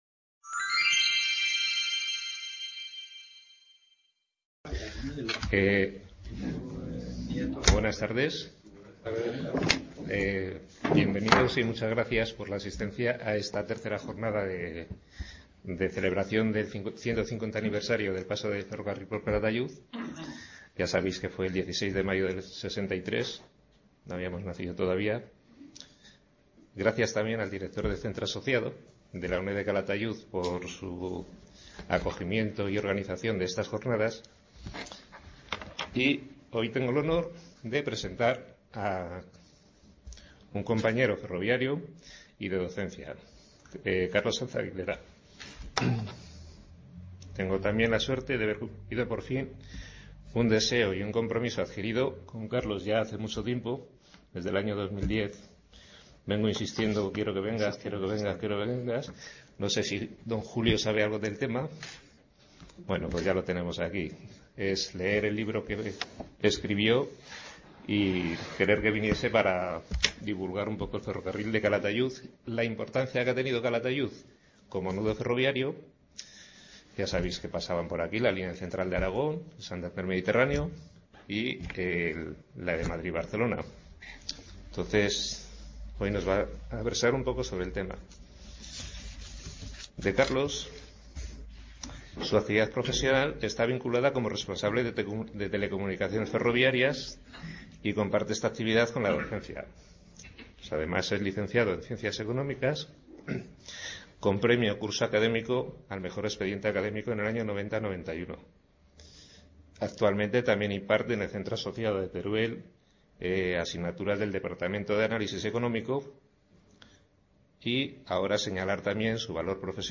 Description Ciclo de seis conferencias sobre el ferrocarril, impartidas por expertos en la materia y abiertas a todo el público. Con motivo del 150 Aniversario de la llegada del ferrocarril a la Estación de Calatayud.